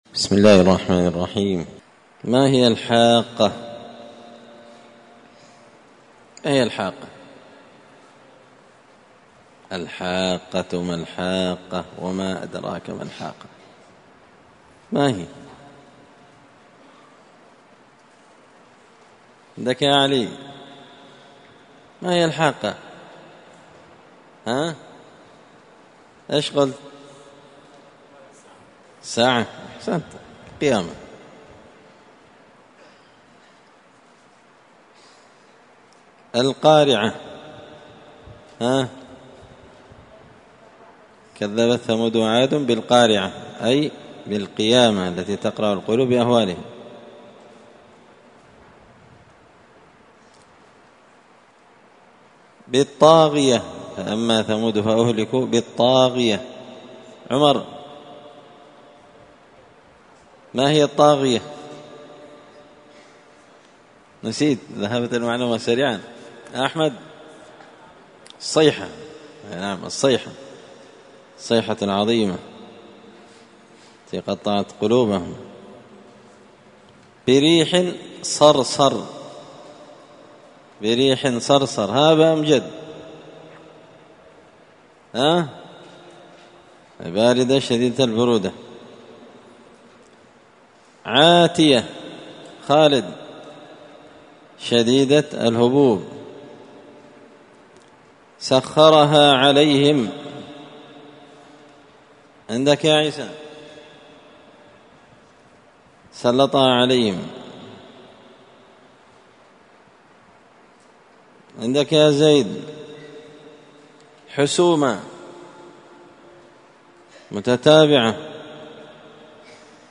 زبدة الأقوال في غريب كلام المتعال الدرس السبعون (70)